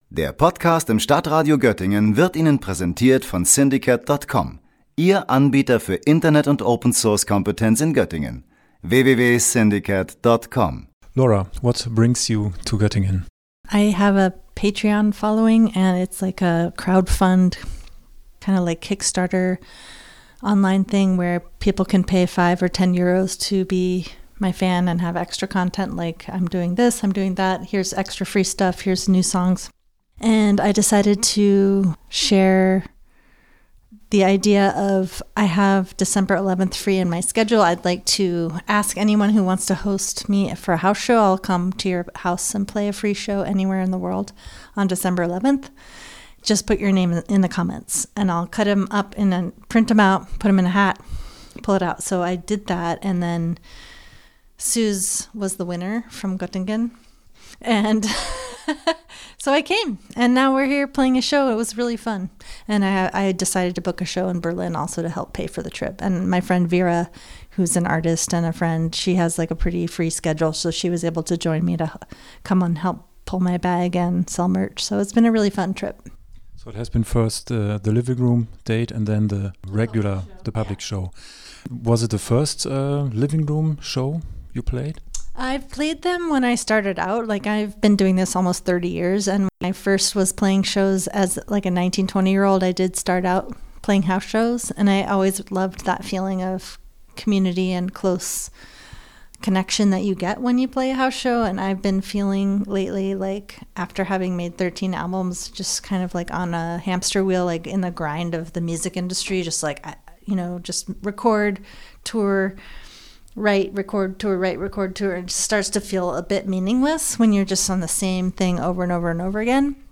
interview_laura_veirs_stadtradio_goettingen_engl-playout.mp3